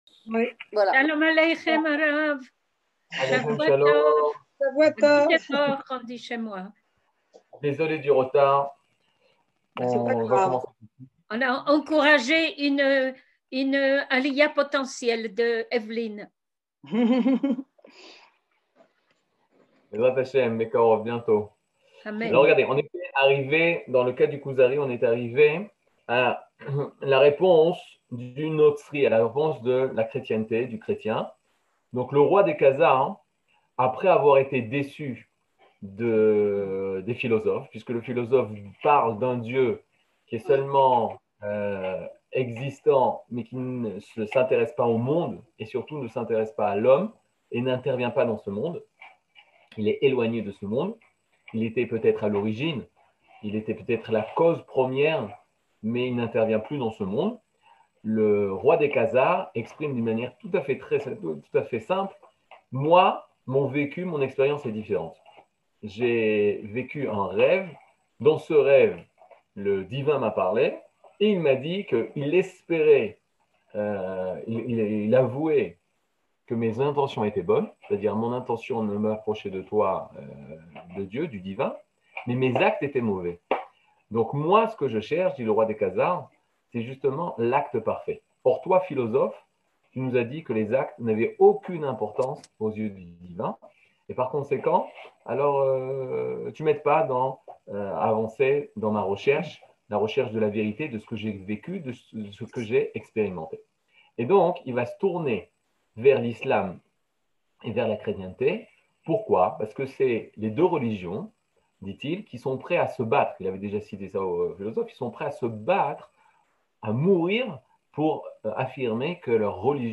Catégorie Le livre du Kuzari partie 7 00:58:57 Le livre du Kuzari partie 7 cours du 16 mai 2022 58MIN Télécharger AUDIO MP3 (53.97 Mo) Télécharger VIDEO MP4 (125.14 Mo) TAGS : Mini-cours Voir aussi ?